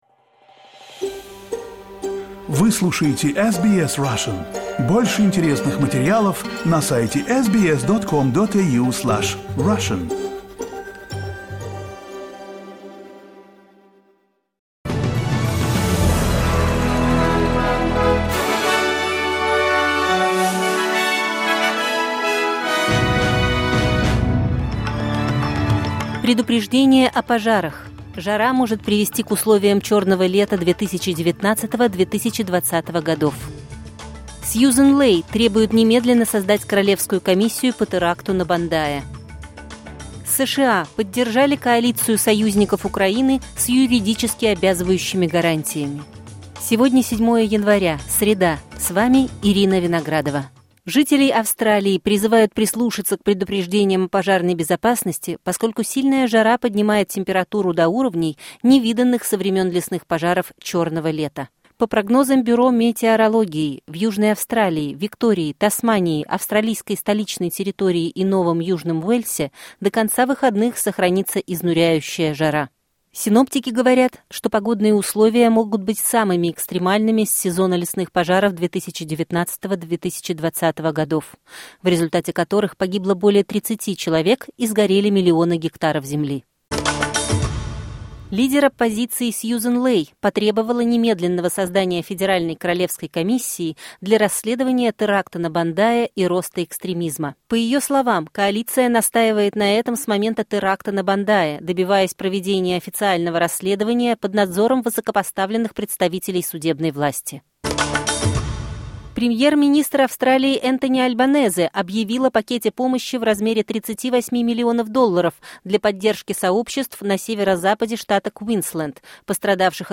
Новости SBS на русском языке — 7.01.2026